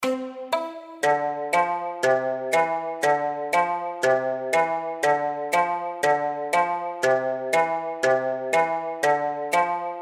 Instrument - Mandolim Key: C Ionian Scale Ranger: 1 Octave Start Octave: C3 Track Tempo: 2x Arpeggio: Ascending